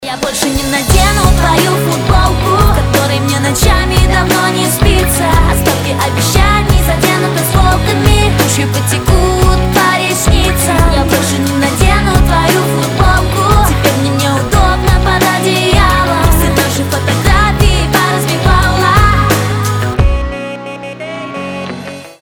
• Качество: 256, Stereo
поп
женский вокал